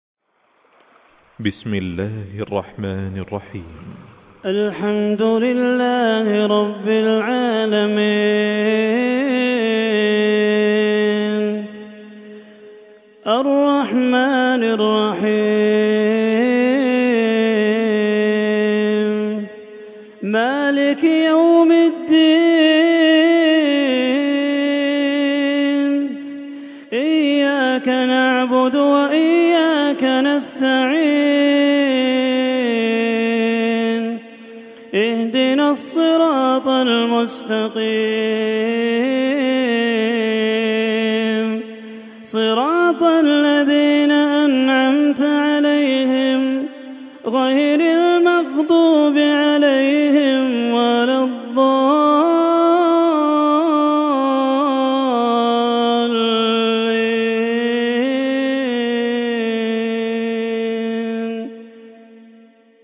Quran recitations
Murattal-Hafs